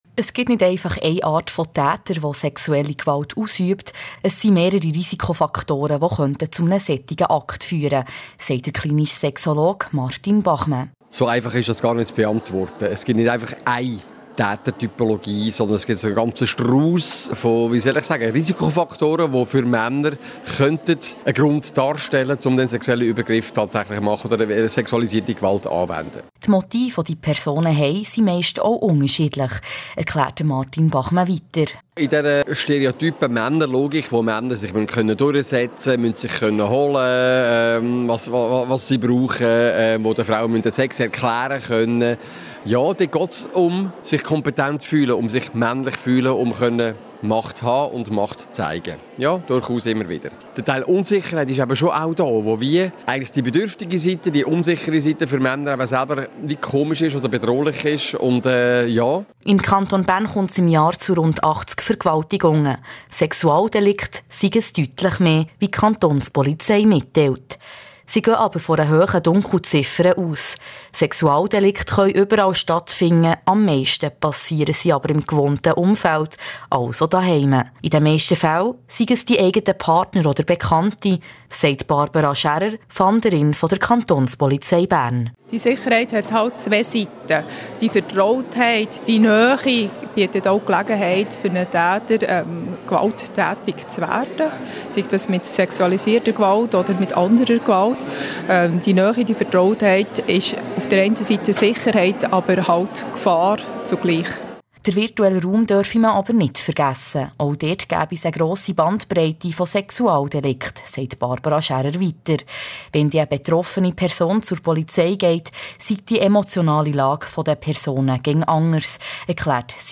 Sexualisierte_Gewalt_Bern_Sexologe_und_Fahnderin_erzaehlen.mp3